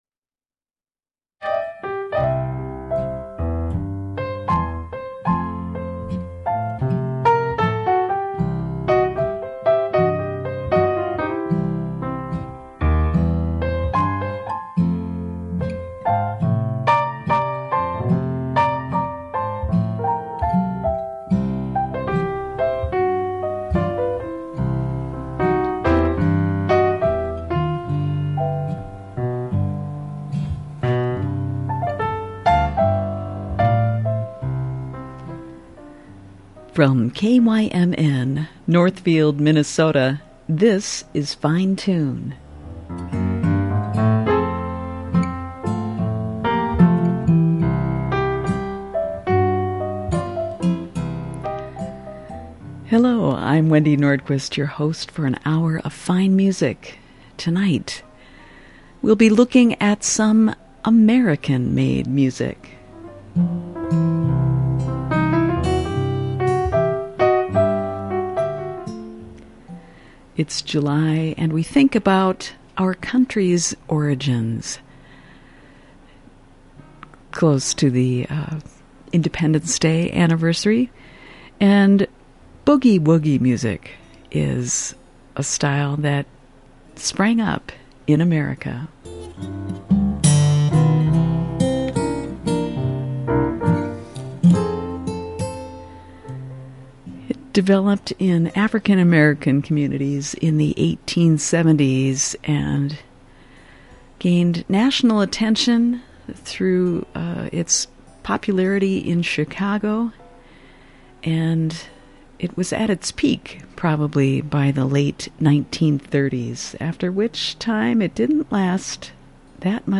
Presenting an influential and truly American musical style